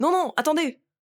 VO_ALL_Interjection_02.ogg